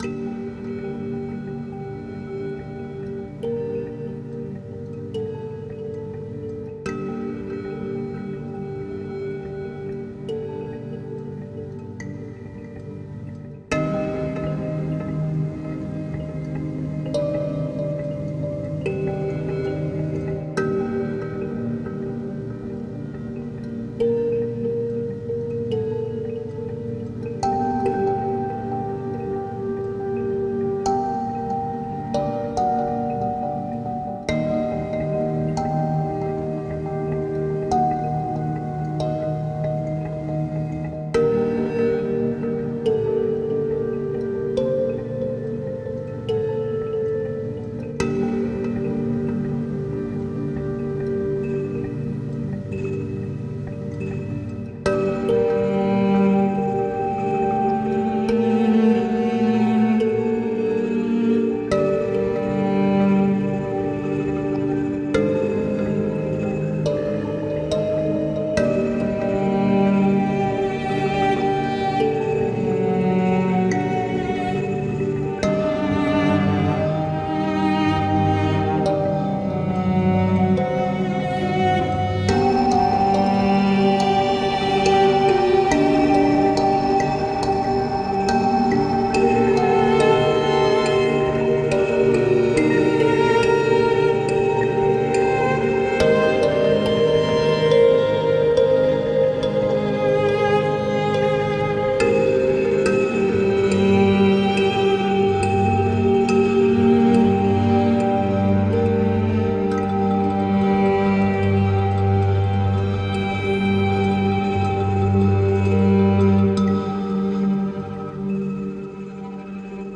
这样会产生柔和的，圆润的声音，与演奏乐器时产生的尘埃环完美配合。
包括情绪：微环，磁带降解，纹理和声音异常
3种演奏风格：橡胶，手指，指甲
动态：sm57
电容：AT 4033